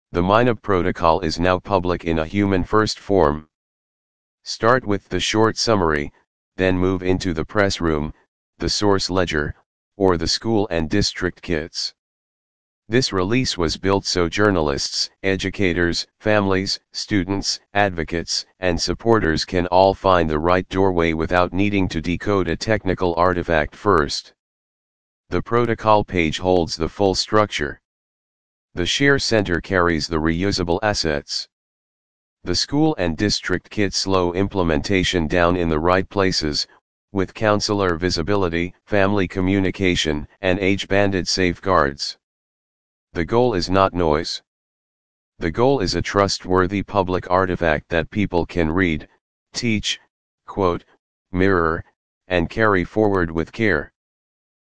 A short silent explainer, a longer narrated version, audio-only, and transcripts live here.
minab-explainer-audio.mp3